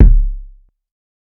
Kicks
TC3Kick6.wav